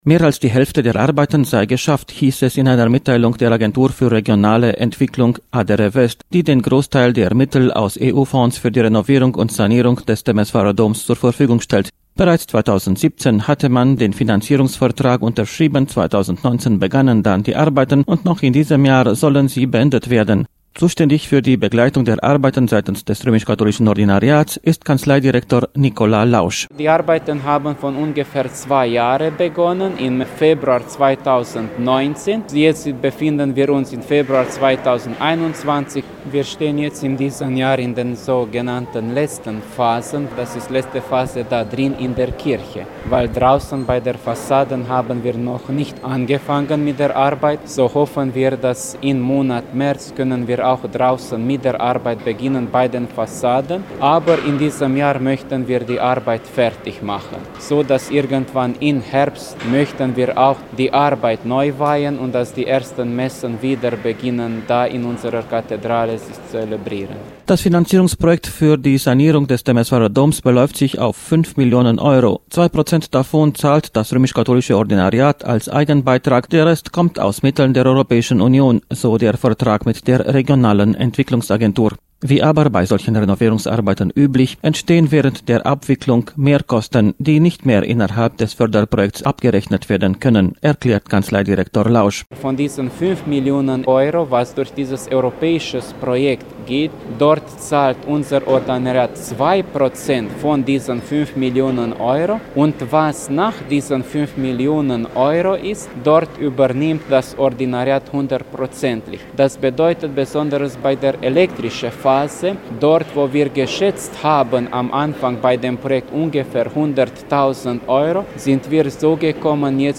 Die Renovierungsarbeiten am römisch-katholischen Dom zu Temeswar sind zu etwas mehr als die Hälfte getätigt worden. Das Projekt sieht Innen- sowie Außenrenovierungen vor und es wird großteils aus europäischen Mitteln finanziert. Um über den Stand der Arbeiten zu berichten luden das römisch-katholische Ordinariat und die Agentur für Regionale Entwicklung in diesem Monat zu einer Baustellenbesichtigung ein.